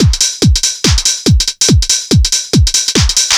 NRG 4 On The Floor 047.wav